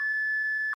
Felsignal_Man_verpanel_Fel_Kod_
Tags: hemlarm